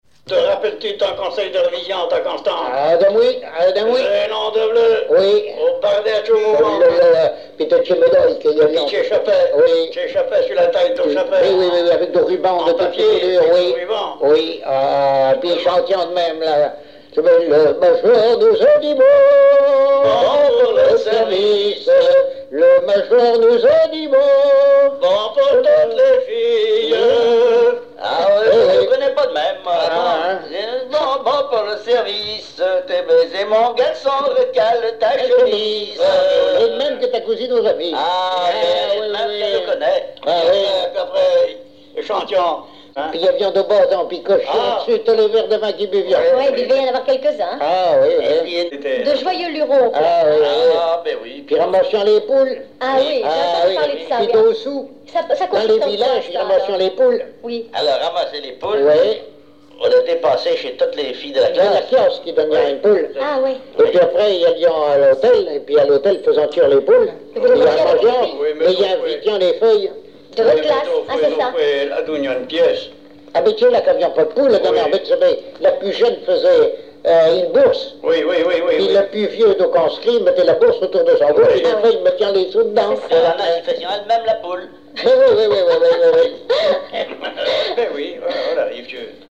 extrait de l'émission La fin de la rabinaïe sur Alouette FM
Langue Patois local
Catégorie Témoignage